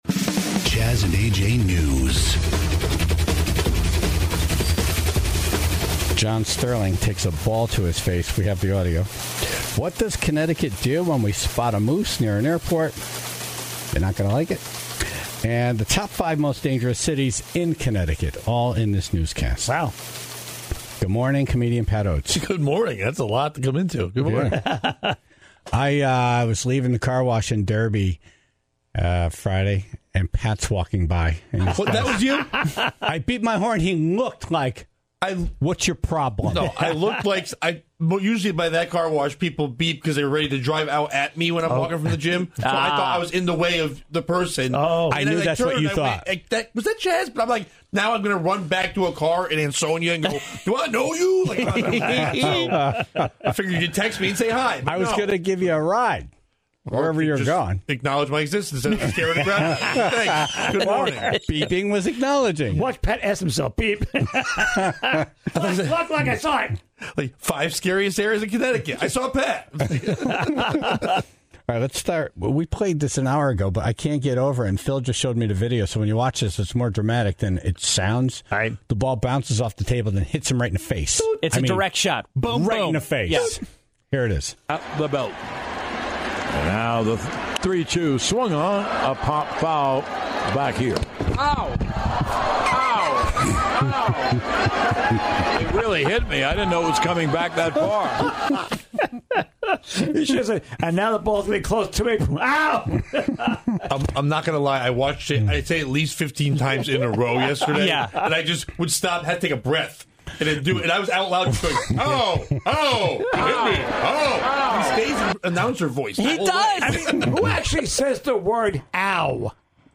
Very similar to the tortilla slap challenge, they filled their mouths with water and then smacked each other with flyswatters.